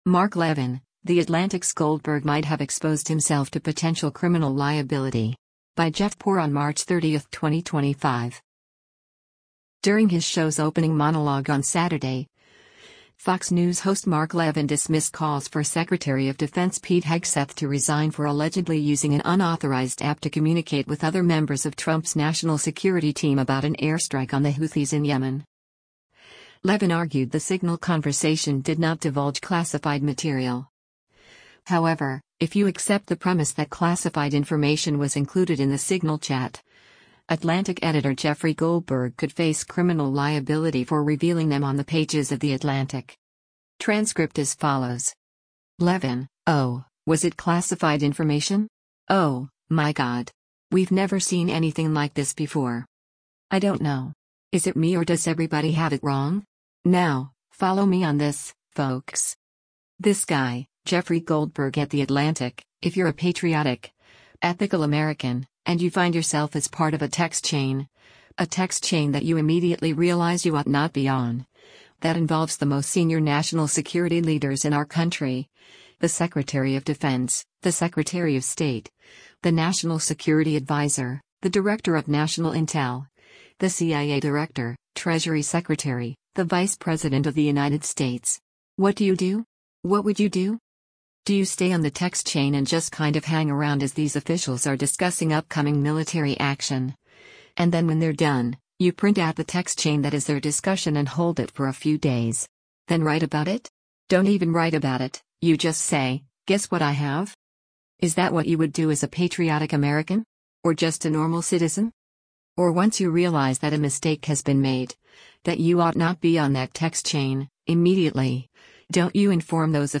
During his show’s opening monologue on Saturday, Fox News host Mark Levin dismissed calls for Secretary of Defense Pete Hegseth to resign for allegedly using an unauthorized app to communicate with other members of Trump’s national security team about an airstrike on the Houthis in Yemen.